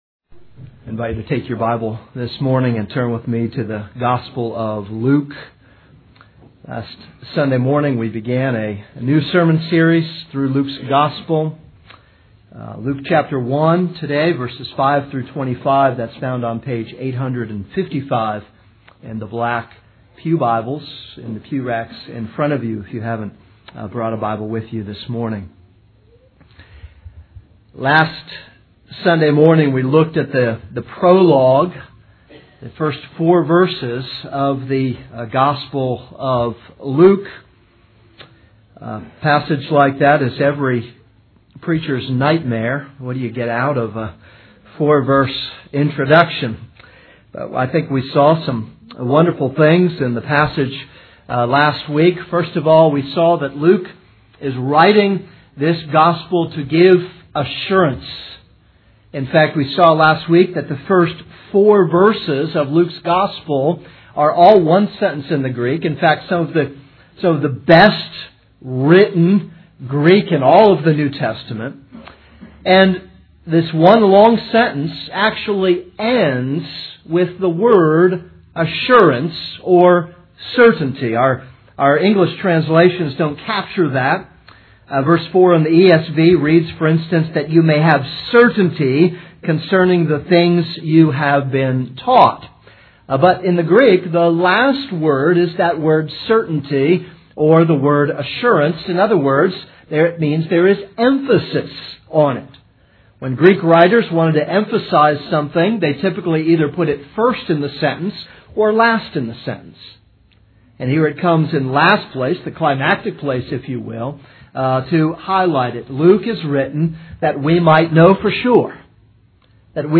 This is a sermon on Luke 1:5-25.